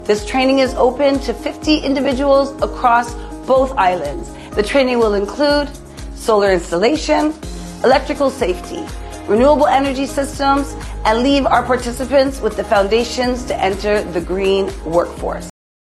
This is the voice of Permanent Secretary of the Prime Minister’s Office, Ms. Naeemah Hazelle, giving more details: